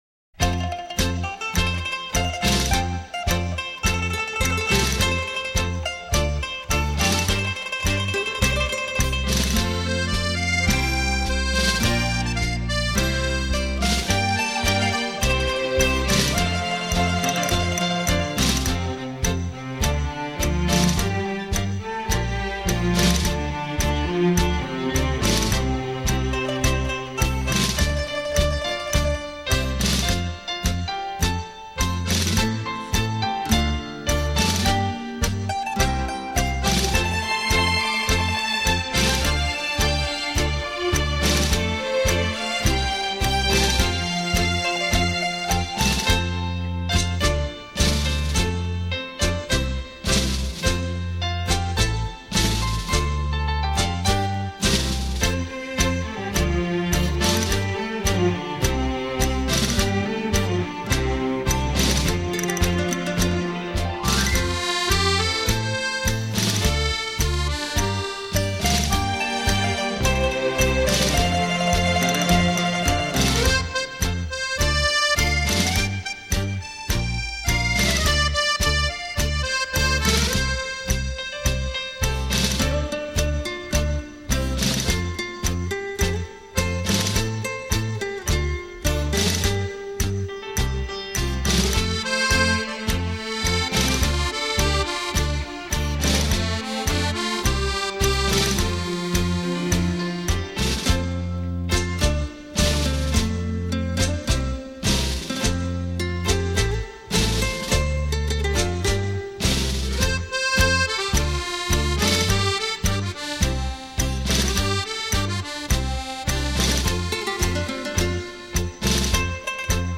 重新诠释并融入舞曲风格保证令人耳目一新